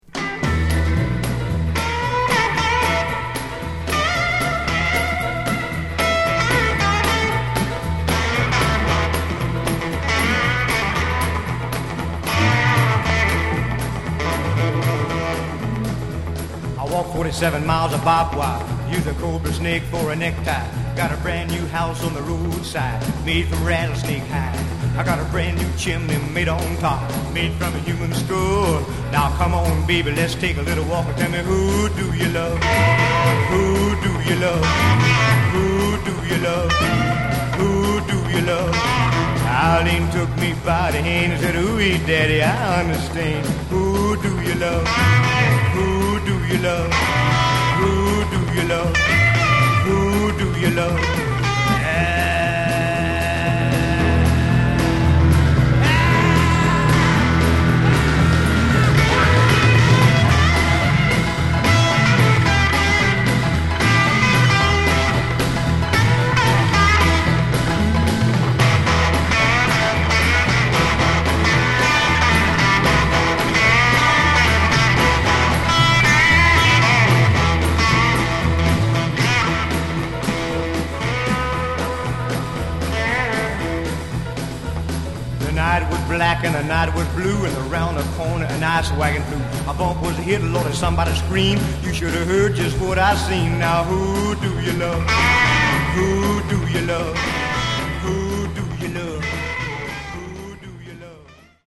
Genre: Garage/Psych